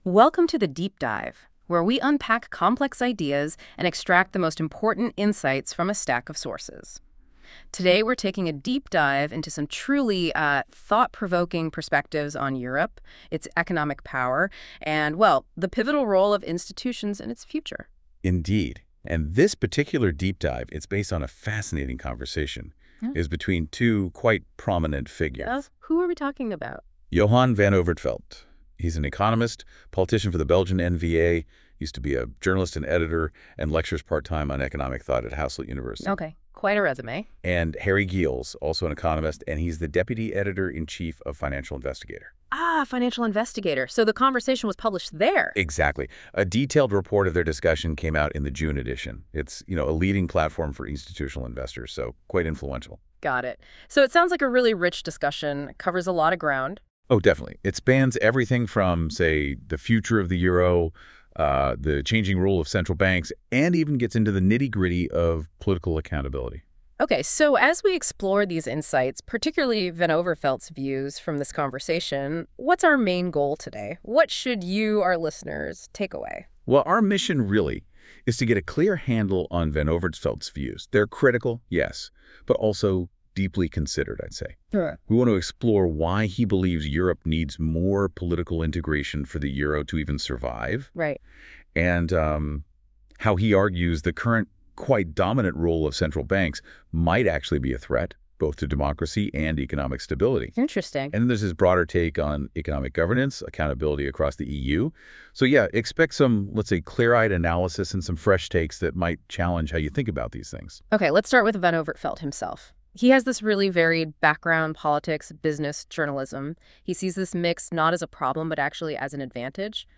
Podcast on interview with Johan Van Overtveldt, economist and politician
// Listen to AI podcast